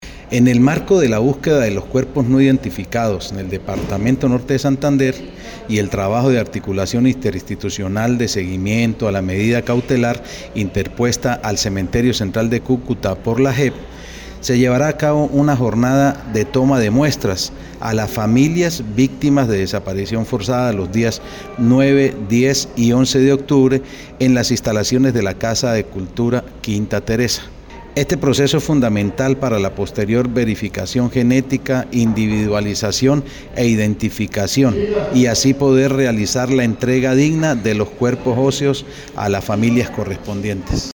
1.Audio de Jhonny Peñaranda, secretario de Gobierno